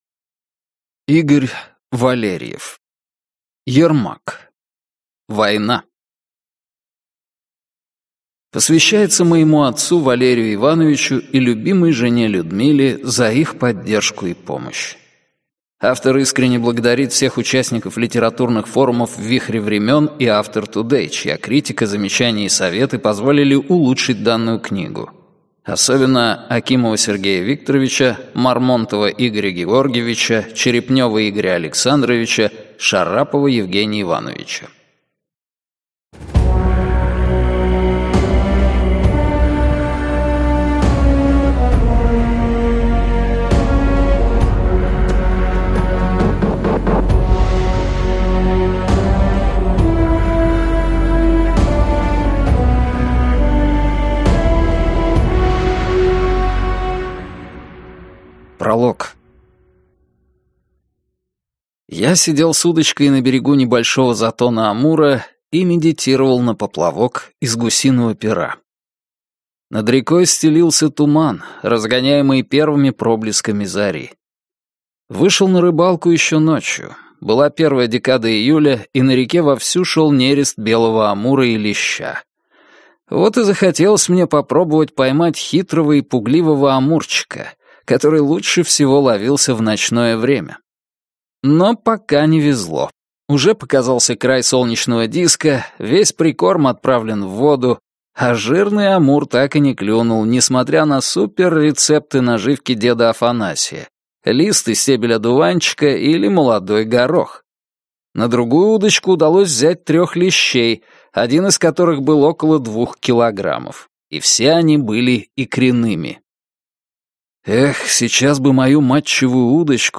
Аудиокнига Ермак. Война | Библиотека аудиокниг